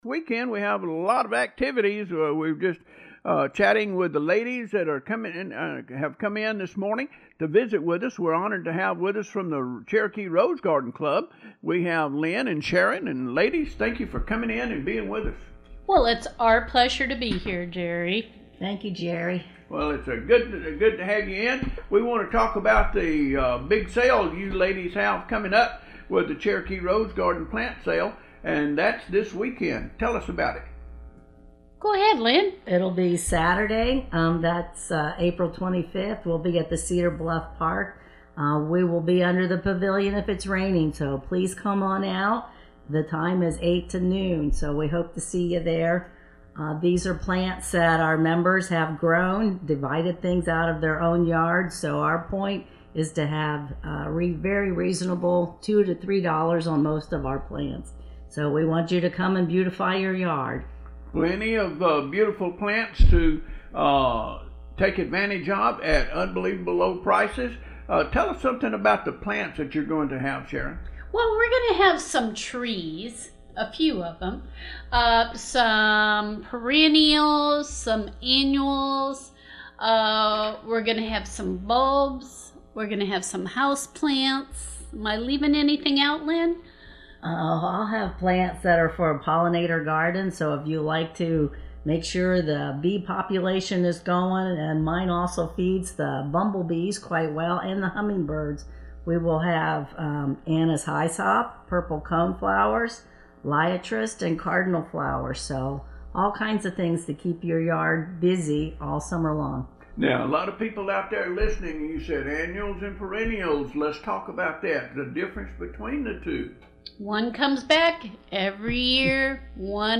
On-Air Interview